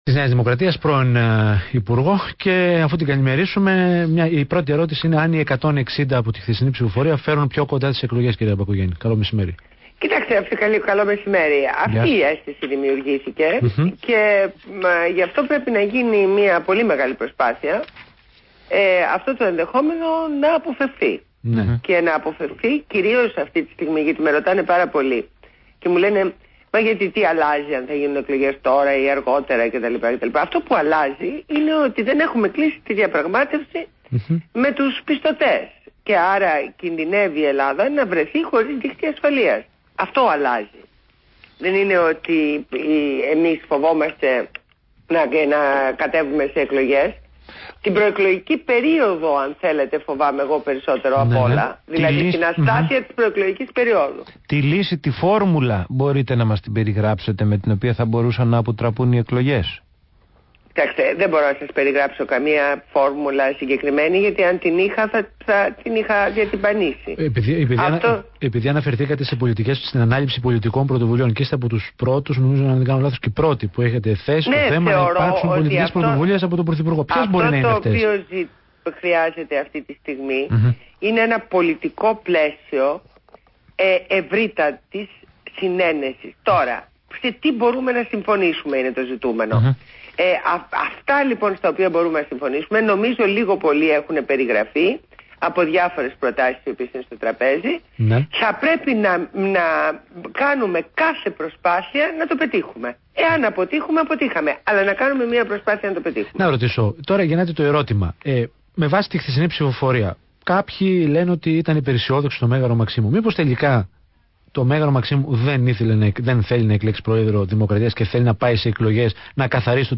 Συνέντευξη στο ραδιόφωνικό σταθμό ΒΗΜΑ FM